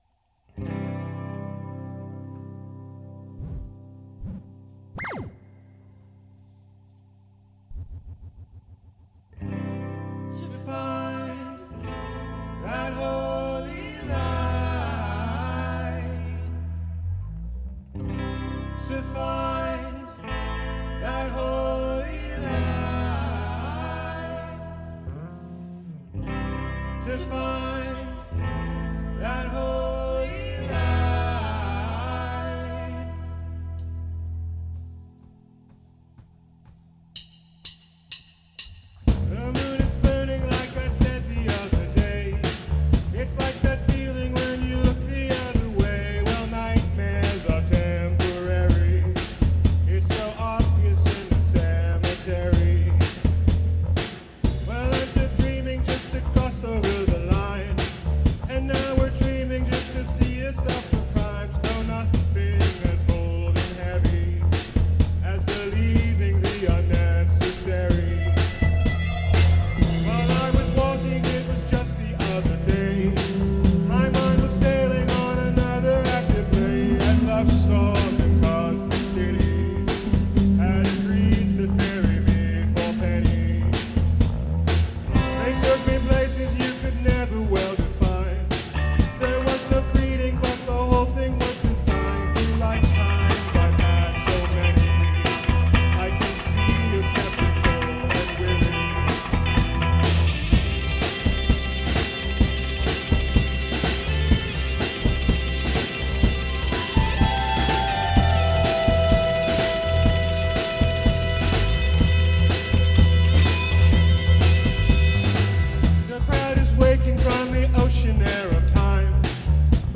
experimental psychedelic rock band
lead vocals/guitar/effects/synth
vocals/bass
drums/electronics/visuals
Electronic